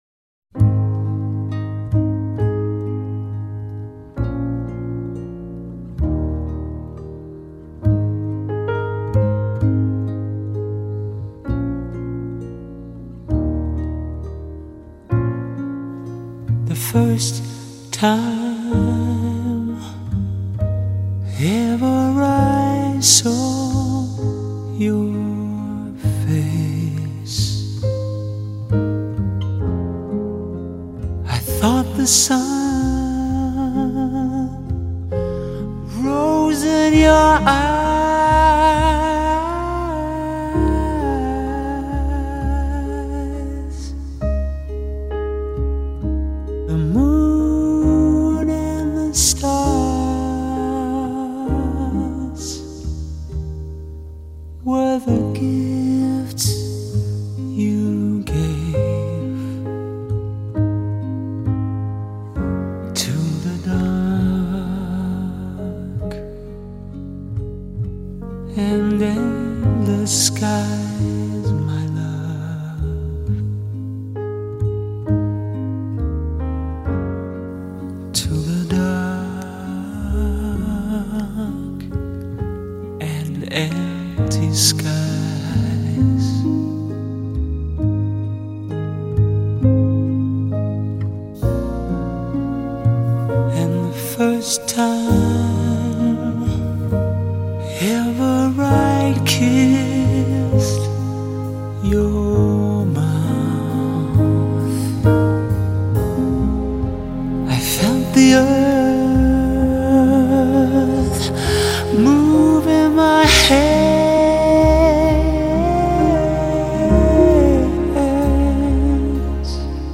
擁有醇熟歌聲的小情人